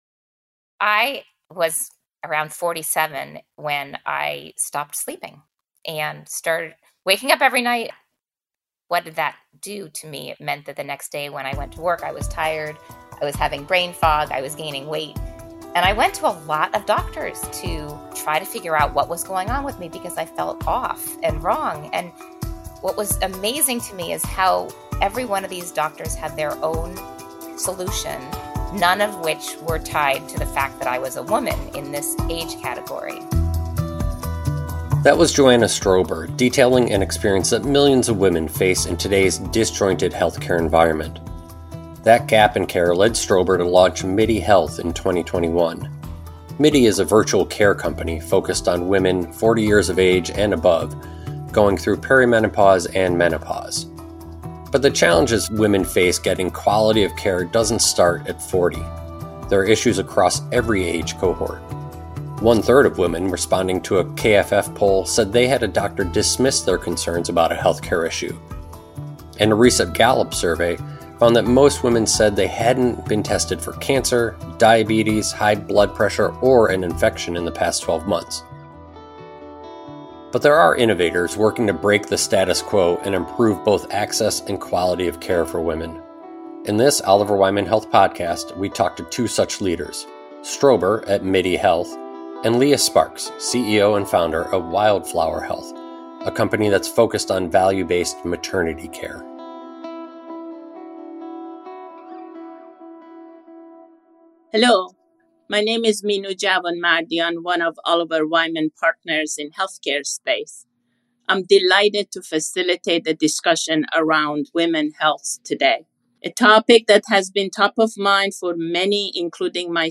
Women face a fractured healthcare environment. In this podcast, two innovators share how they are integrating women’s healthcare into the broader ecosystem.